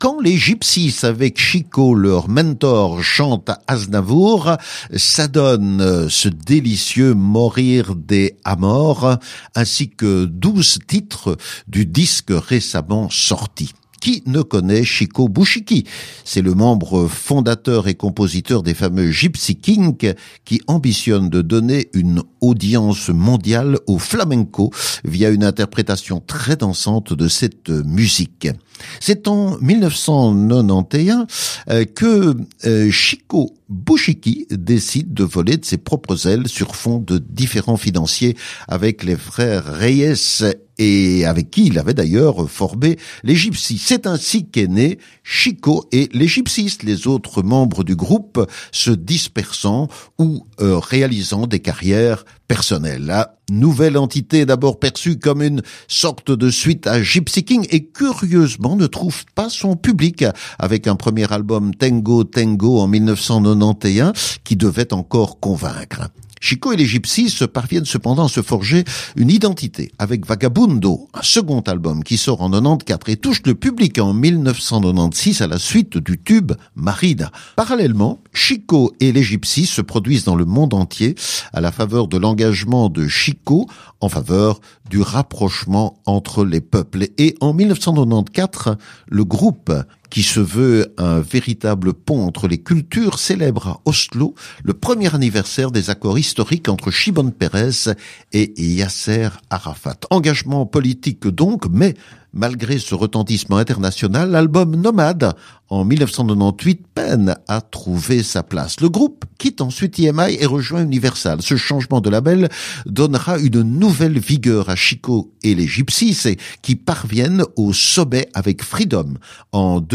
groupe musical français de rumba, flamenca, pop, rock